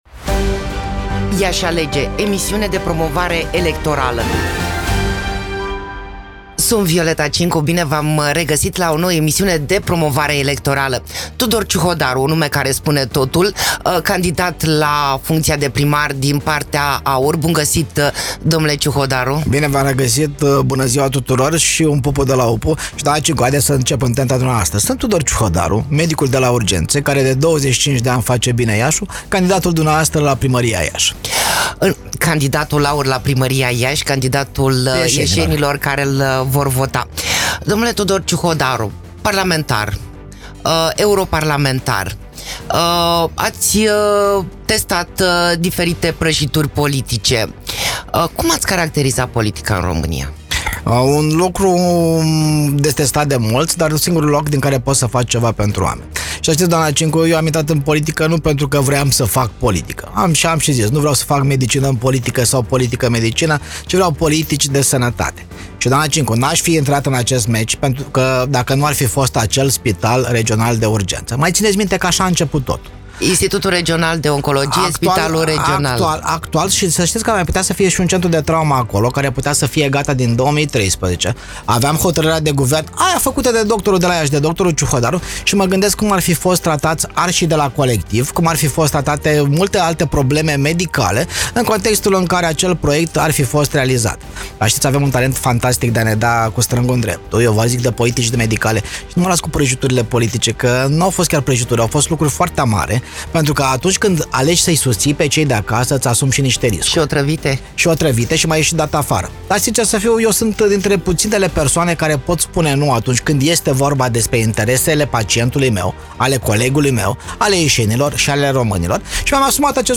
Candidatul AUR la primăria Iași, candidatul ieșenilor care-l vor vota se prezintă singur. Nu a uitat numărul partidelor politice prin care a trecut și spune că nu s-a plictisit deloc.